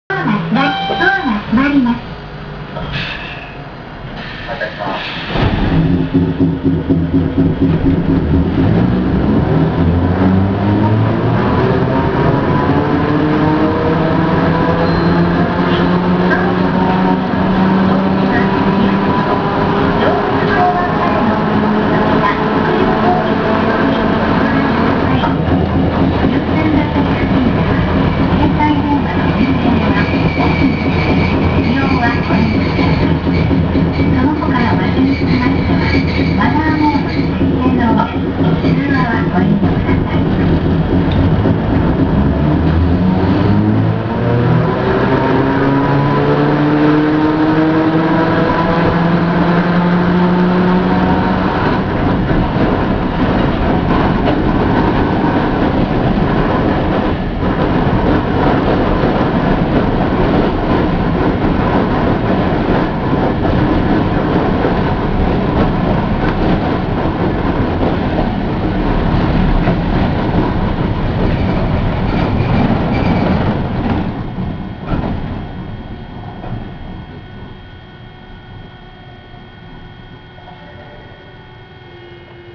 ・50形走行音
【城南線】県庁前→市役所前（1分22秒：449KB）…68号にて
一応前・中・後期で分けたのですが基本的に音は同じで、全て吊り掛け式。個人的には、数ある路面電車の中でもかなり派手な音を出す部類に感じました。